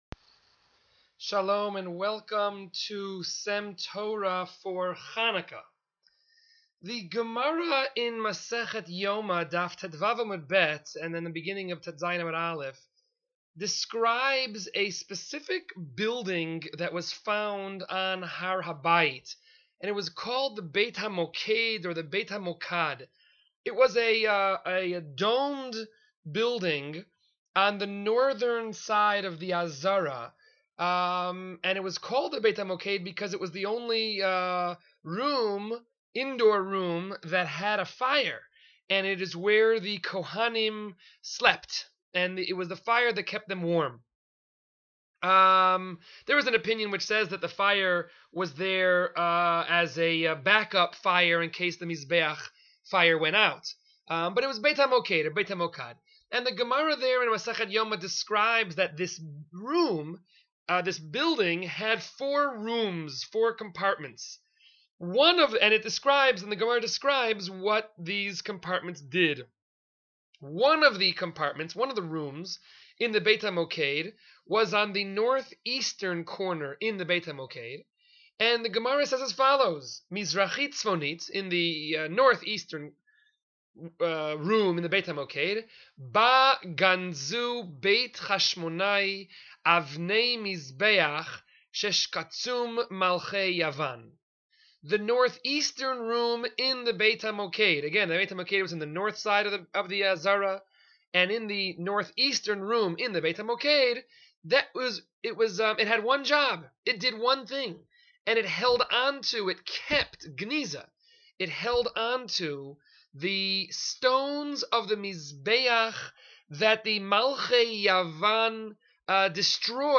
S.E.M. Torah is a series of brief divrei Torah delivered by various members of the faculty of Sha�alvim for Women.